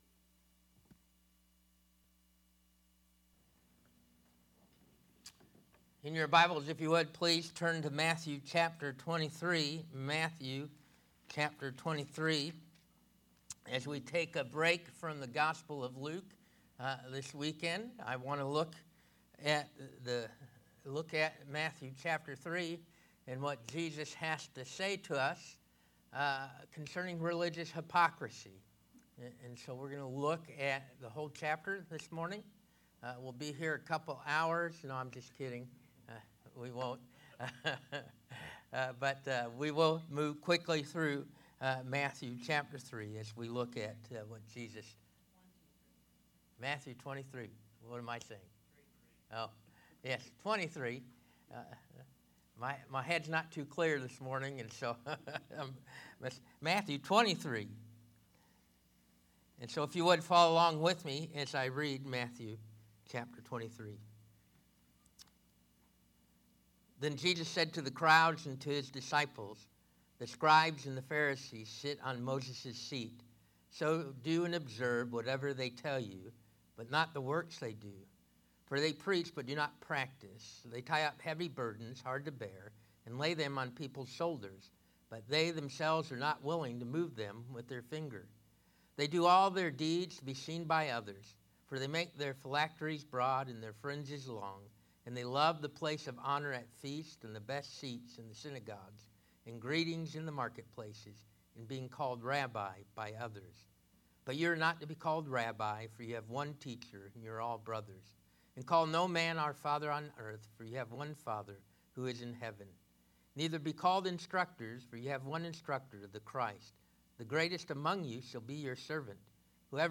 North Stafford Baptist Church Sermon Audio Is Jesus is the Christ?